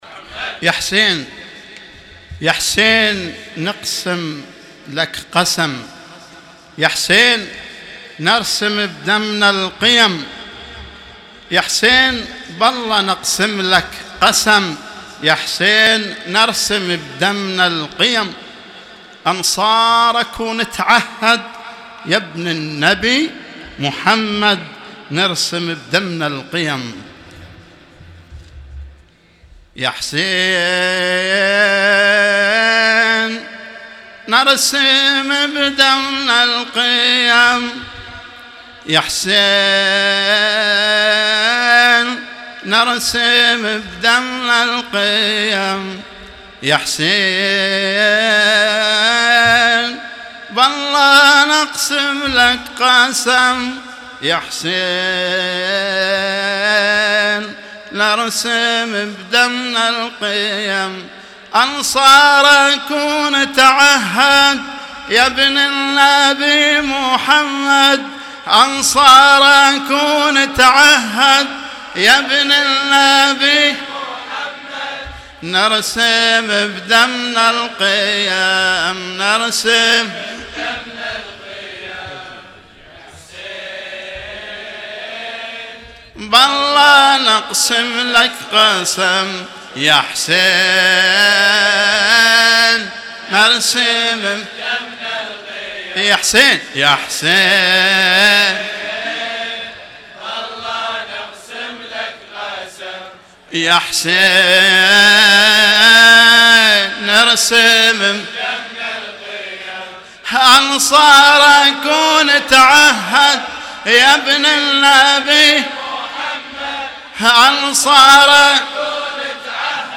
لطمية الليلـ 05 ـة الجزء (02)